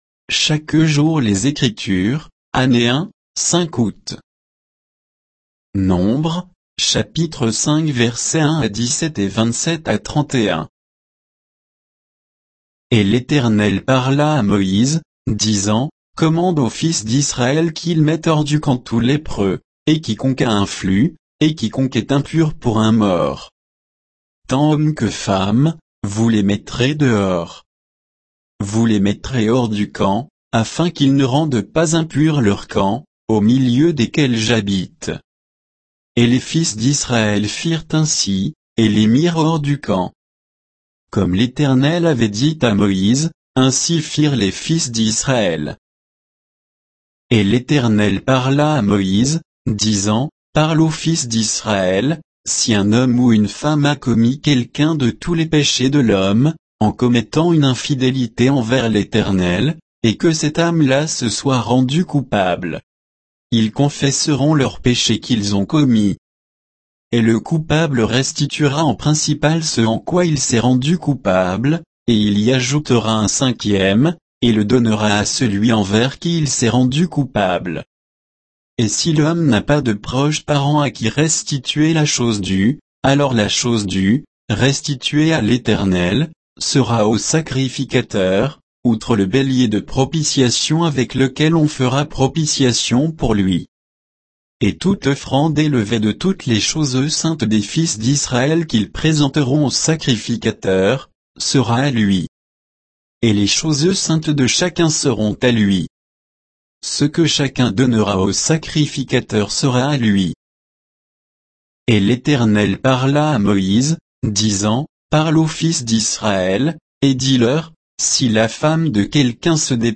Méditation quoditienne de Chaque jour les Écritures sur Nombres 5, 1 à 17, 27 à 31